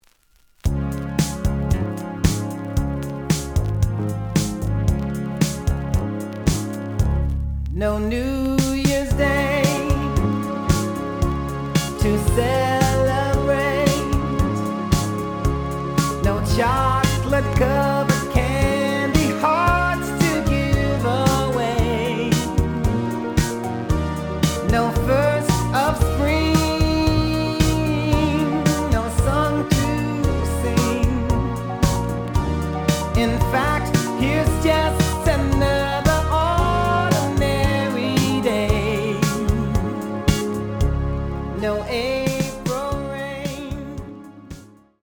The audio sample is recorded from the actual item.
●Genre: Soul, 80's / 90's Soul
Slight edge warp.